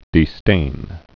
(dē-stān)